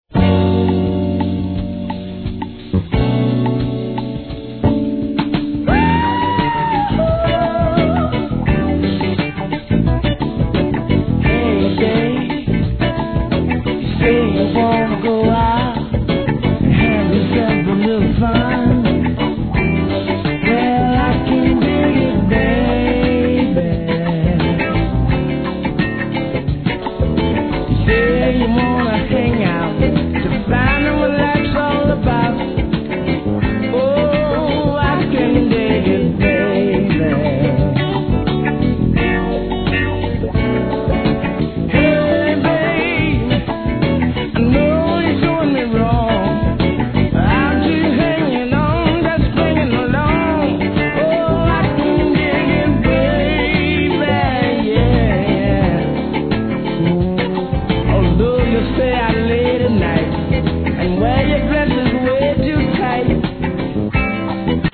¥ 1,320 税込 関連カテゴリ SOUL/FUNK/etc...
マイアミ産スウィートソウルの傑作！